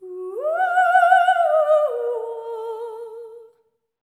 LEGATO 05 -R.wav